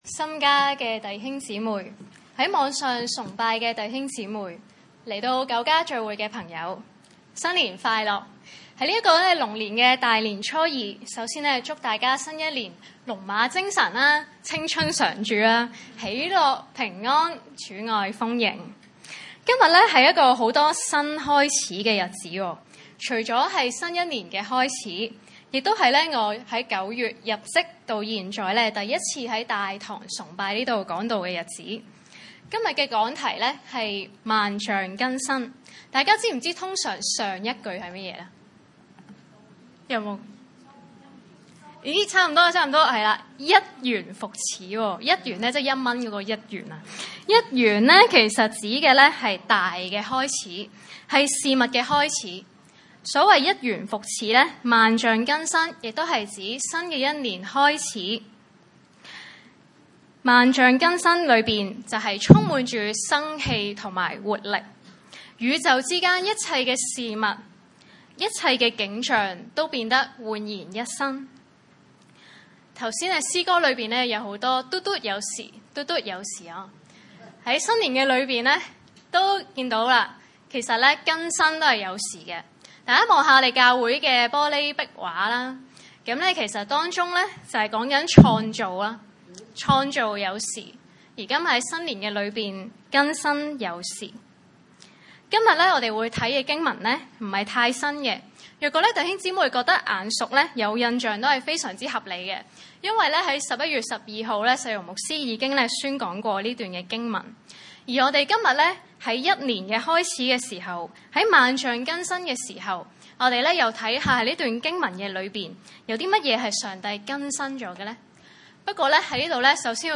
馬可福9︰2-13節 崇拜類別: 主日午堂崇拜 2過了六天，耶穌帶著彼得、雅各、約翰暗暗地上了高山，就在他們面前變了形像， 3衣服放光，極其潔白，地上漂布的，沒有一個能漂得那樣白。